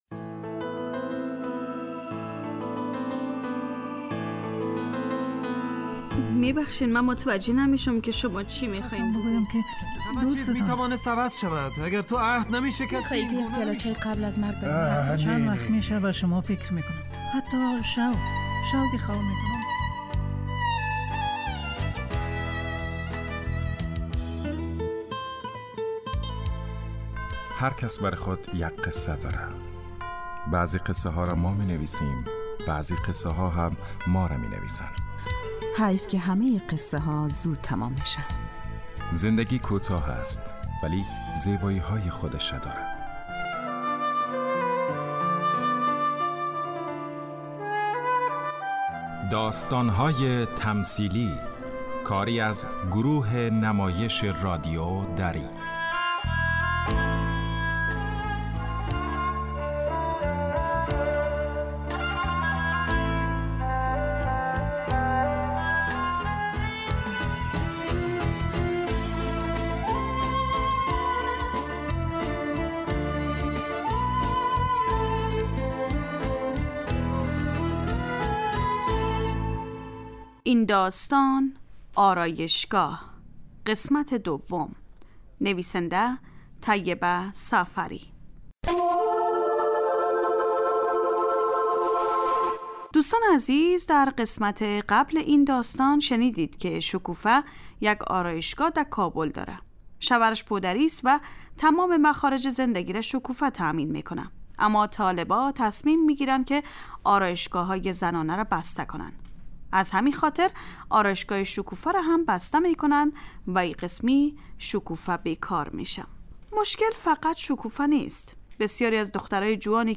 داستانهای تمثیلی نمایش 15 دقیقه ای هستند که هر روز ساعت 4:45 عصربه وقت وافغانستان پخش می شود.